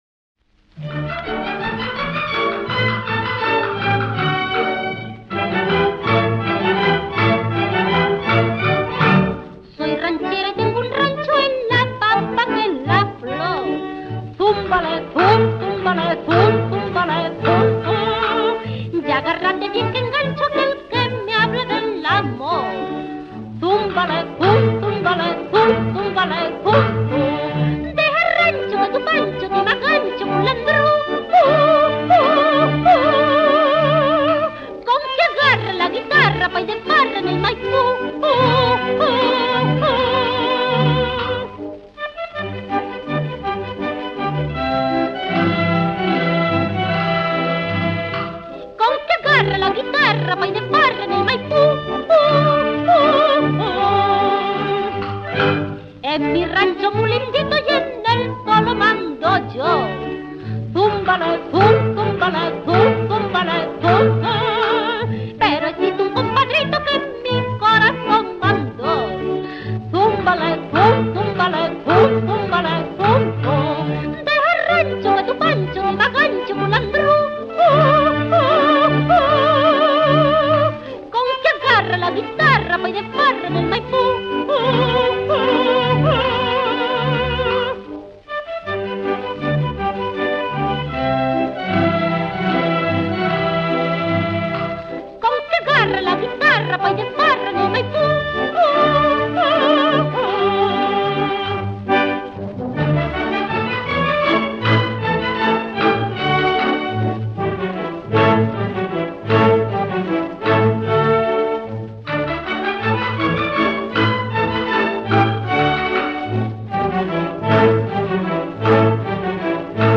Ranchera.
78 rpm